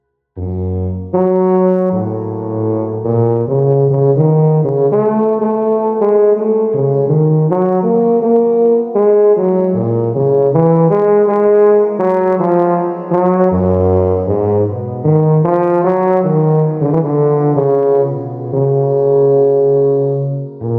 Призвук у тубы.
Записал у продавца через Babyface Pro.
Те же искажения.
Вложения Test-Tuba.wav Test-Tuba.wav 7,6 MB · Просмотры: 98